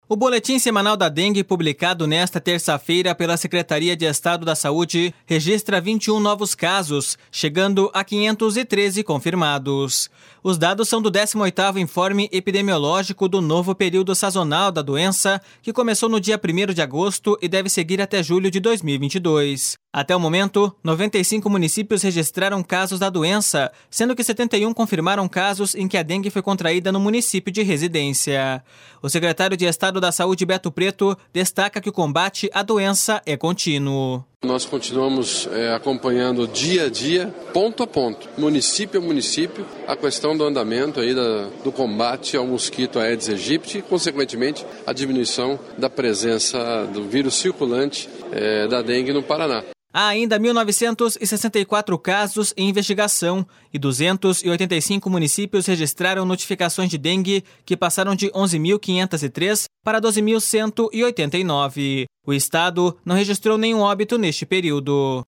O secretário de Estado da Saúde, Beto Preto, destaca que o combate à doença é contínuo.// SONORA BETO PRETO.//